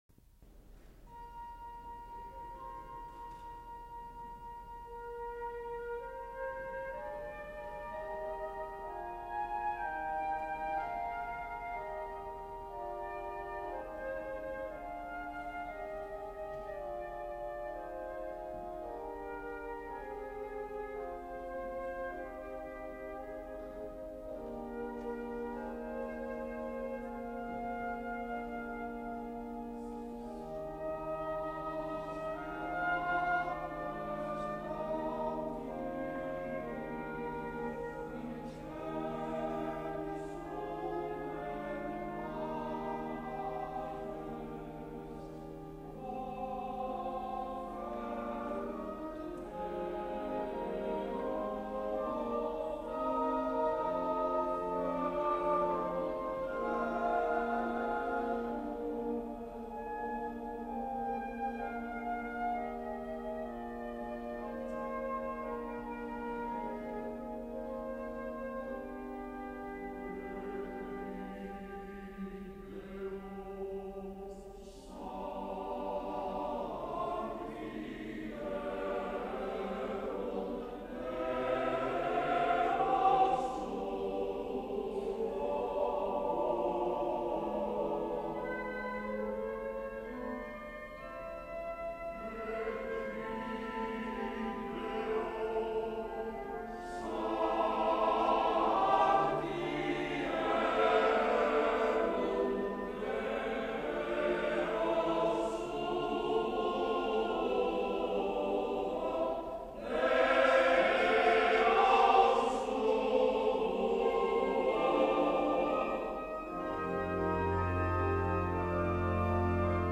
Messa Solenne
S. Alessandro in Colonna